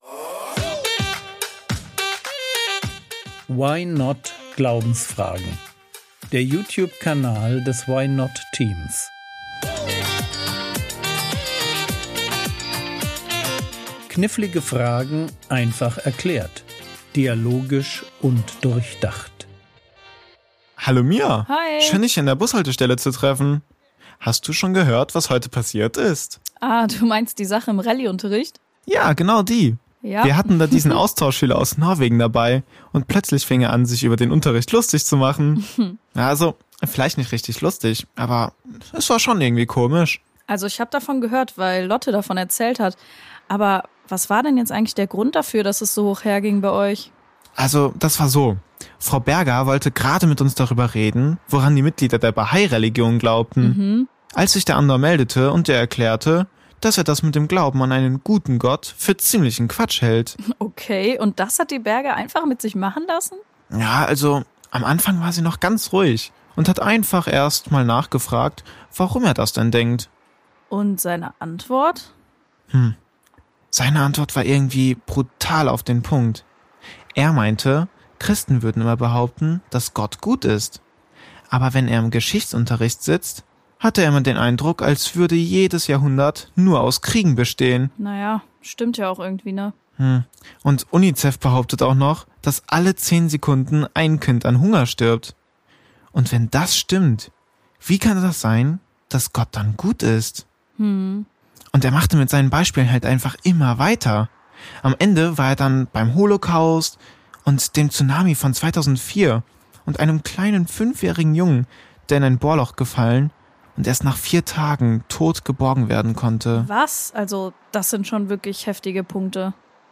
Guter Gott - trotz Leid? ~ Frogwords Mini-Predigt Podcast